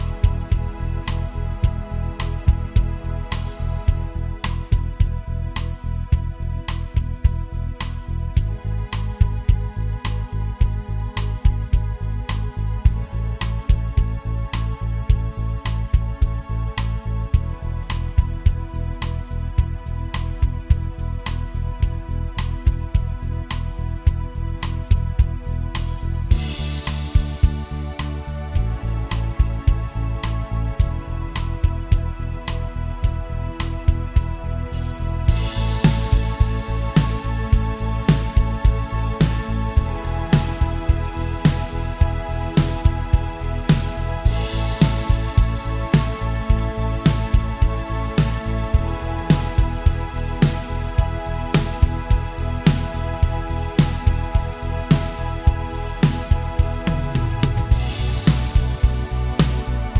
Minus Main Guitars For Guitarists 5:03 Buy £1.50